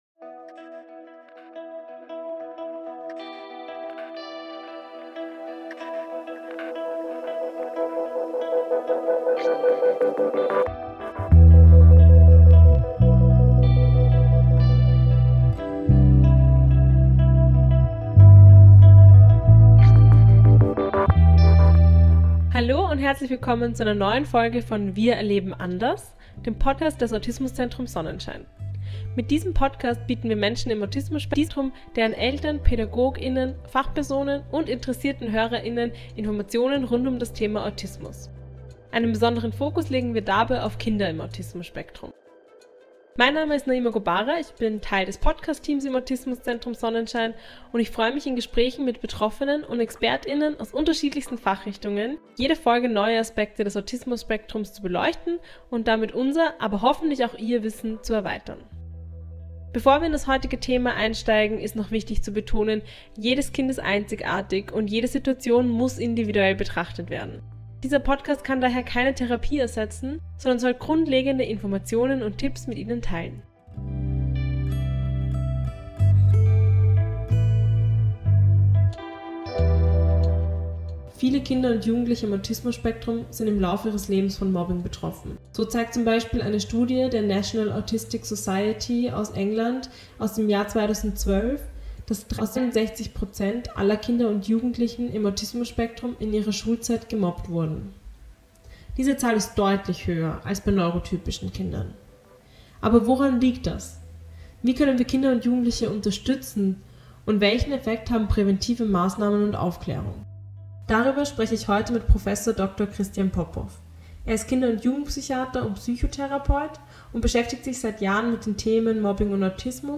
Mobbing: Auswirkungen und Prävention - Ein Gespräch